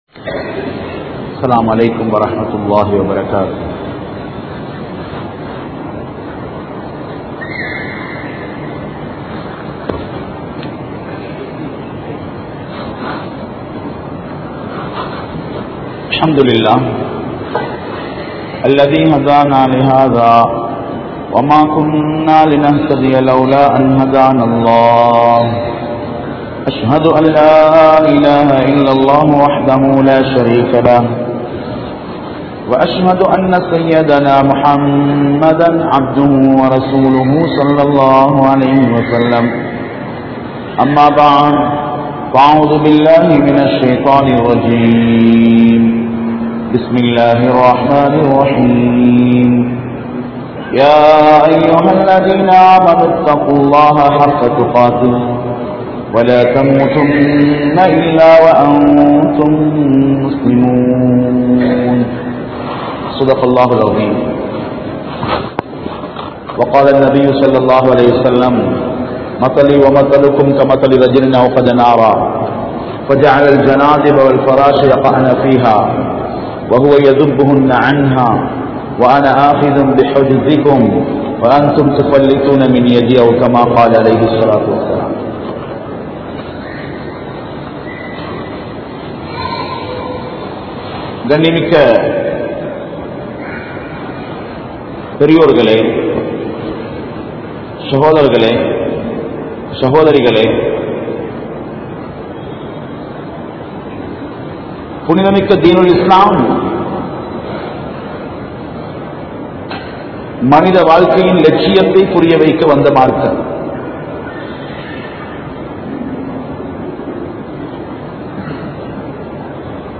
Nabi(SAW)Avarhalin Alahiya Mun Maathirihal (நபி(ஸல்)அவர்களின் அழகிய முன்மாதிரிகள்) | Audio Bayans | All Ceylon Muslim Youth Community | Addalaichenai
Grand Jumua Masjith